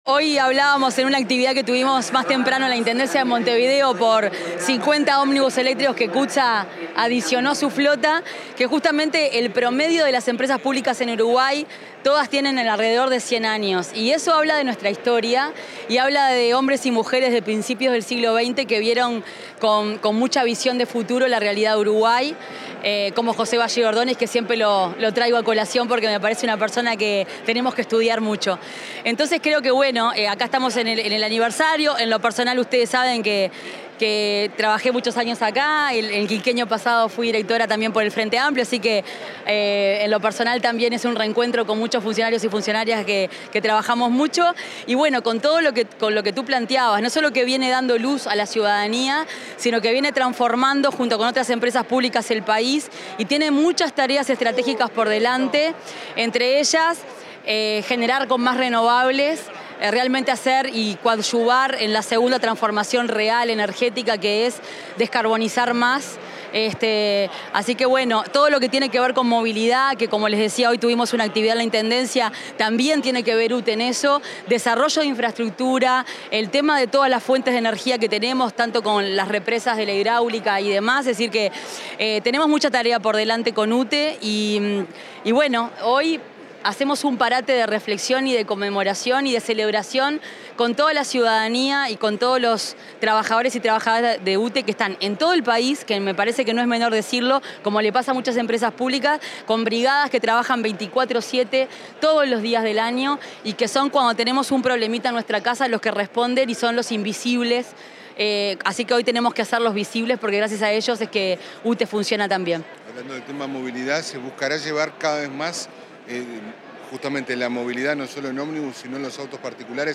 Declaraciones de la ministra de Industria, Fernanda Cardona
La ministra de Industria, Energía y Minería, Fernanda Cardona, dialogó con la prensa antes de participar en la celebración del 113 aniversario de UTE.